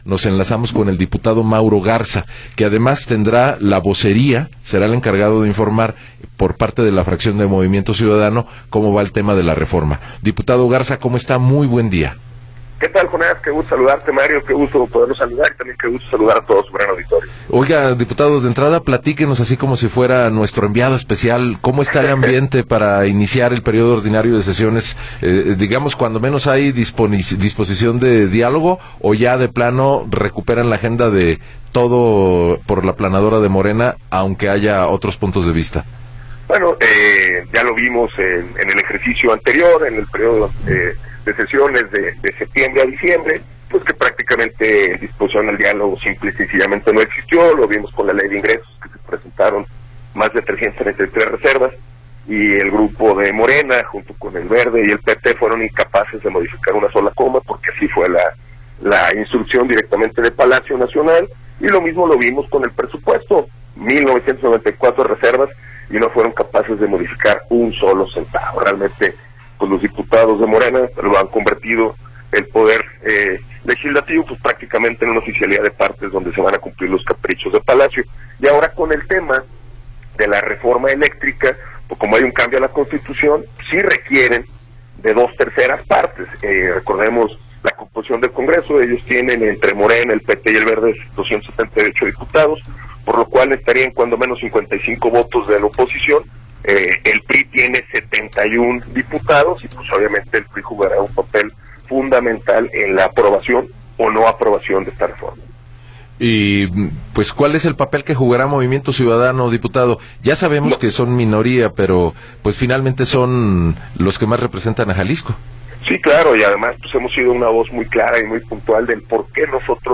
Entrevista con Mauro Garza Marín